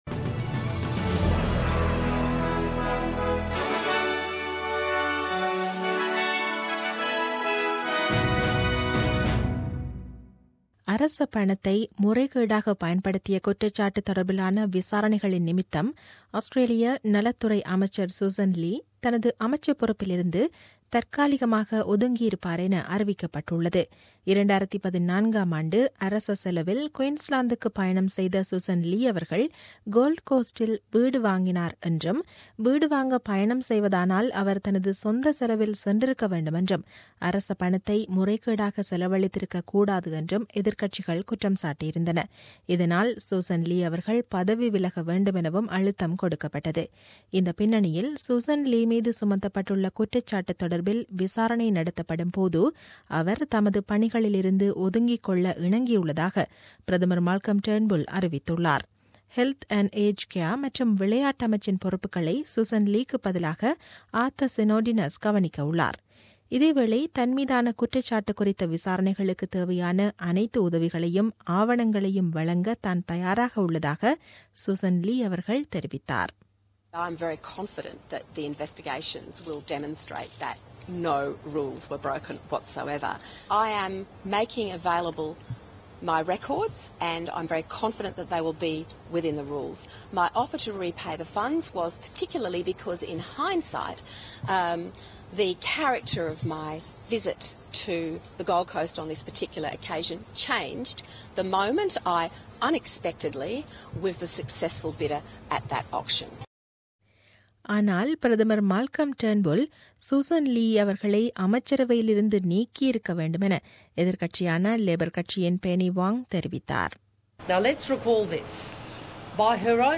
The news bulletin aired on 9 January 2017 at 8pm.